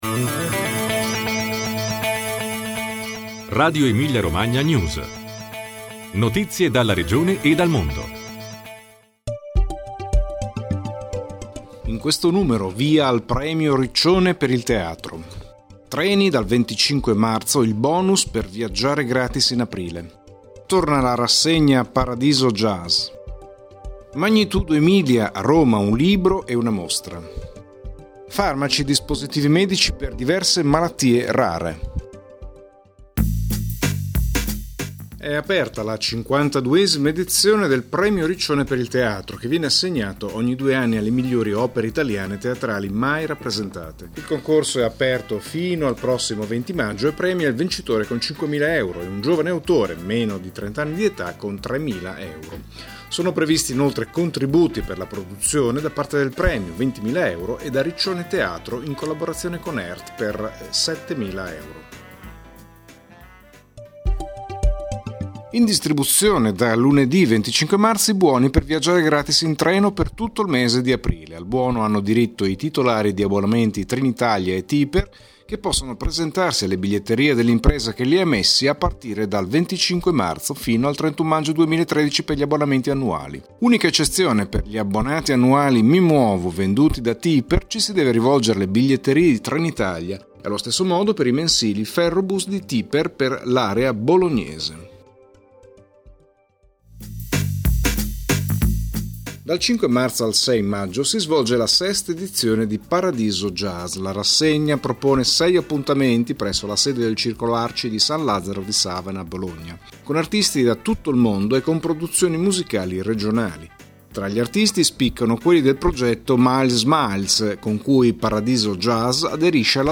Notizie dalla Regione e dal mondo